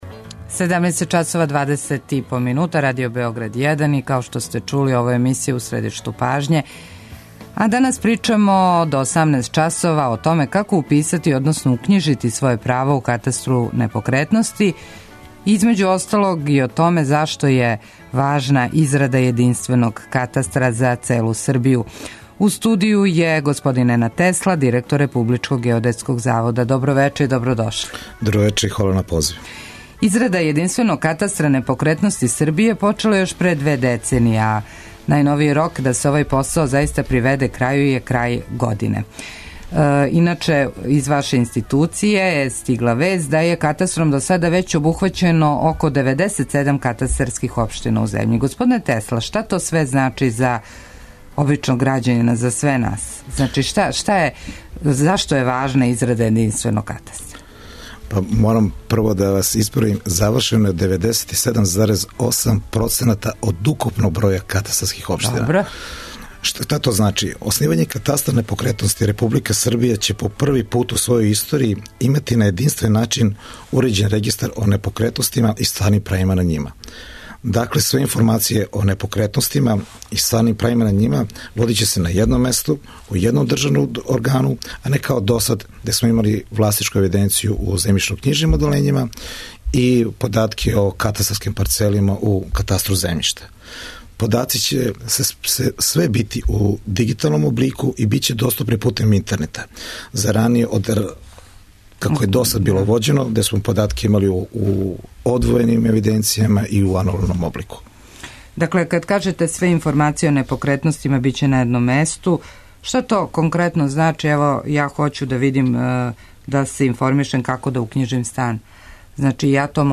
Колико код нас траје упис права на непокретност, шта је све од докумената потребно, да ли је процедура компликована, односно како најбрже и наједноставније укњижити некретнину - тема је наше емисије. Гост је Ненад Тесла, директор Републичког геодетског завода.
преузми : 18.95 MB У средишту пажње Autor: Редакција магазинског програма Свакога радног дана од 17 часова емисија "У средишту пажње" доноси интервју са нашим најбољим аналитичарима и коментаторима, политичарима и експертима, друштвеним иноваторима и другим познатим личностима, или личностима које ће убрзо постати познате.